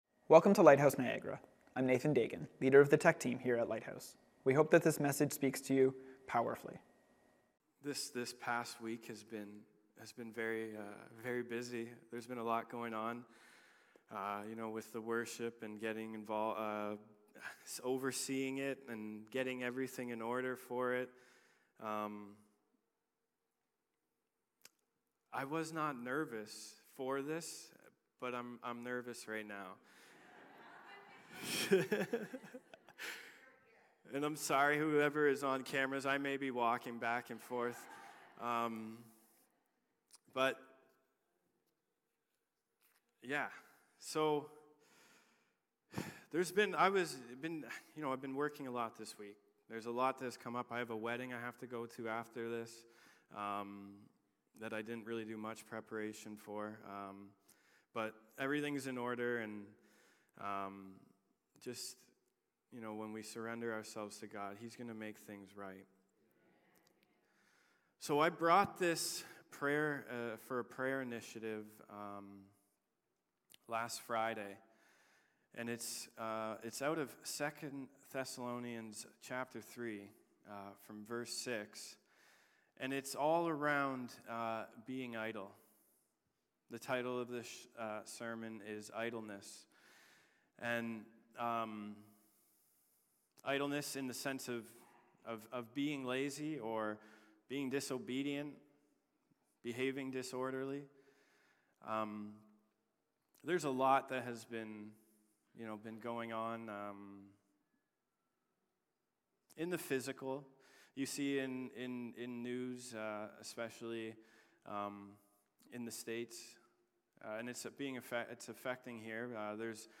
Lighthouse Niagara Sermons